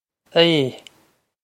Aodh Ay
This is an approximate phonetic pronunciation of the phrase.